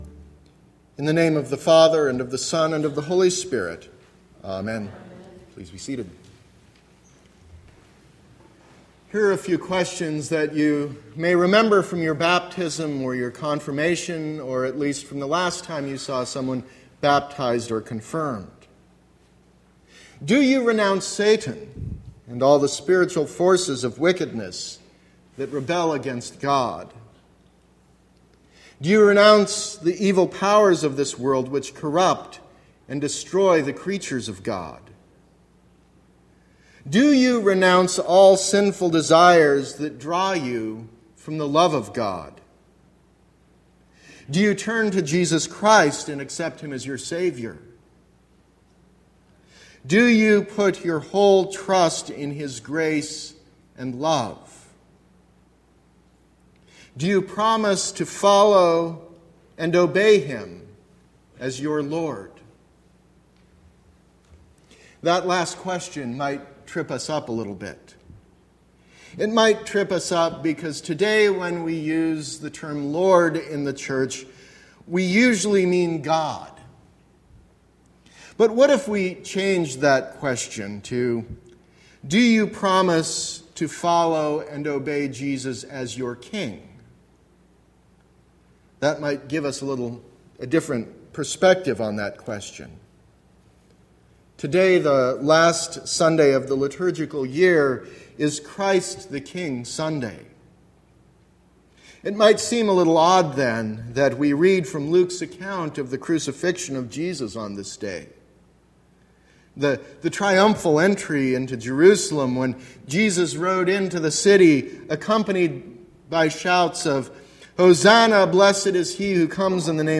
Sermon – November 20, 2016 – Advent Episcopal Church